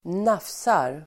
Ladda ner uttalet
nafsa verb (om hundar), nibble [of dogs]Uttal: [²n'af:sar] Böjningar: nafsade, nafsat, nafsa, nafsarDefinition: bita lätt el. på lek